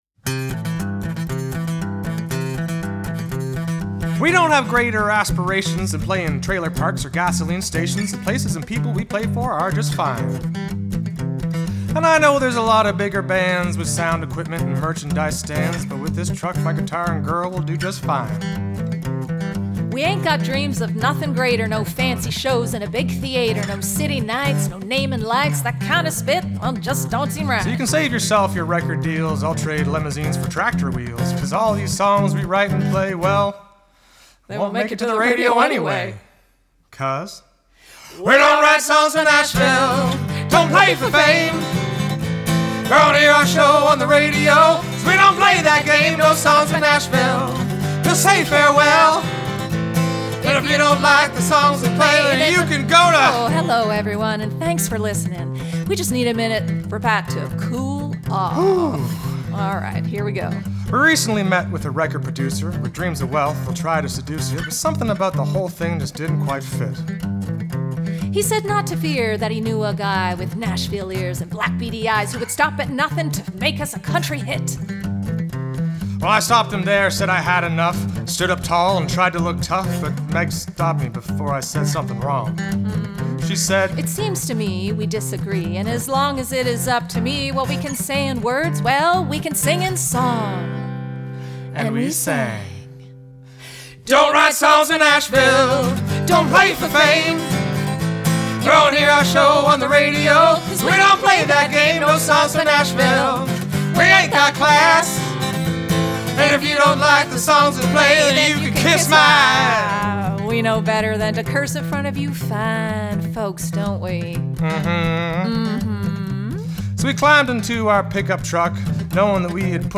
These are demos y'all.